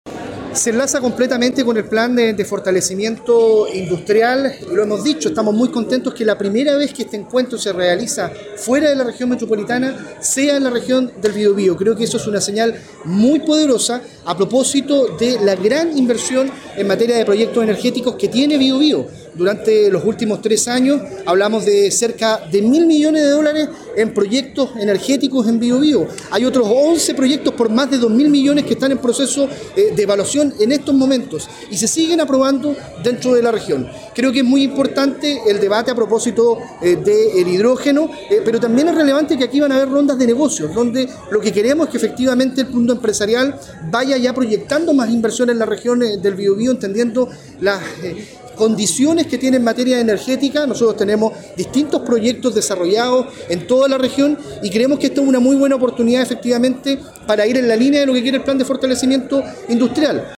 Este martes se realizó en Biobío el Green Hydrogen Summit Chile LAC 2025, instancia donde se abordaron las oportunidades y desafíos del desarrollo energético regional, por primera vez fuera de Santiago, considerando el rol estratégico de la industria local.